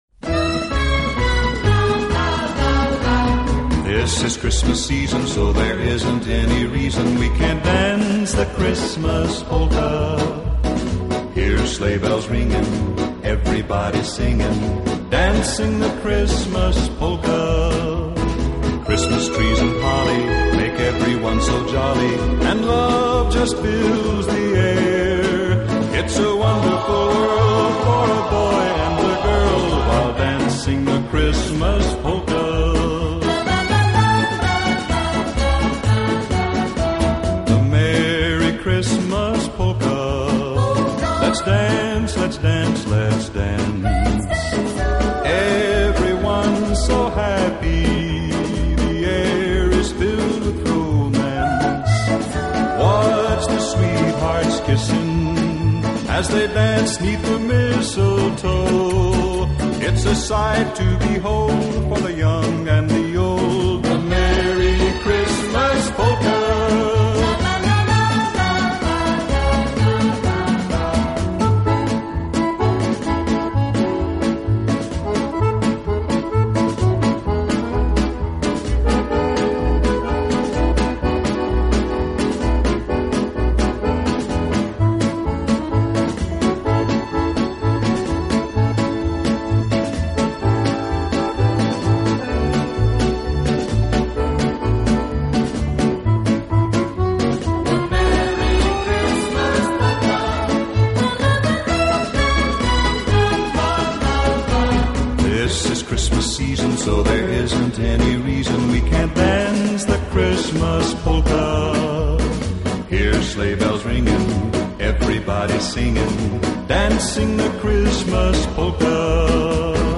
Commentary 9.